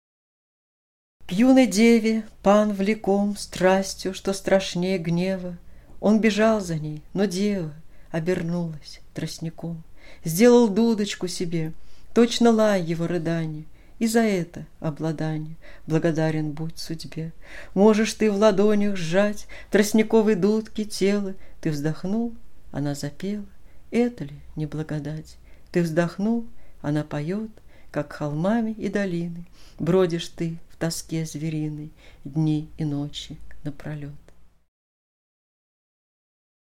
Читает автор)